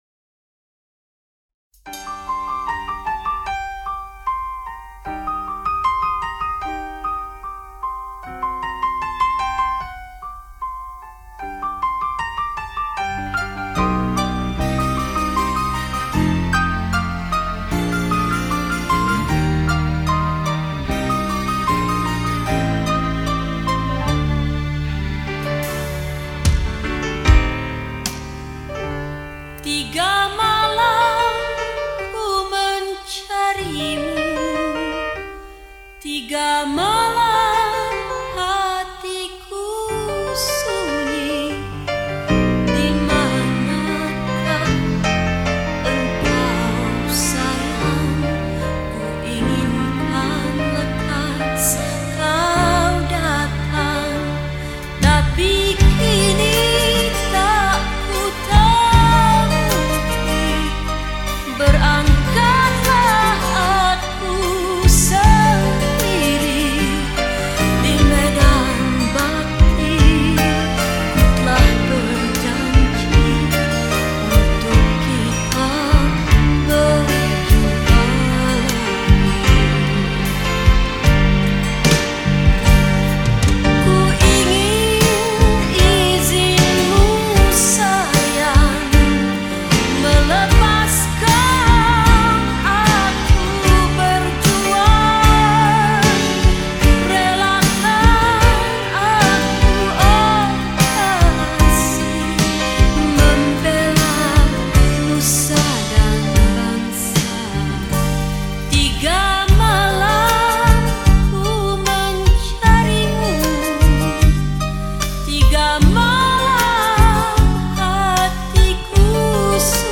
сингапурско–малайзийская певица
кавер немецкой песни о трех мушкетерах..